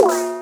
sfxldk02.wav